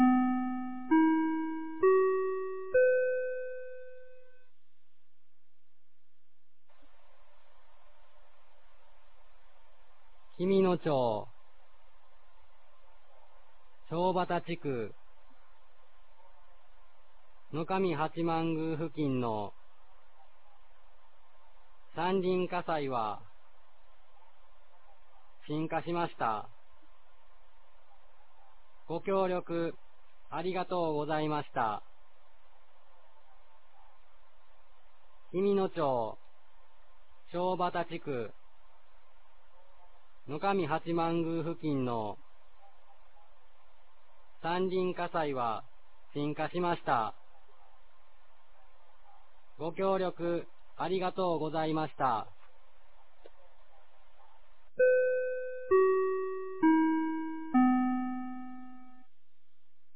2022年05月10日 22時16分に、紀美野町より全地区へ放送がありました。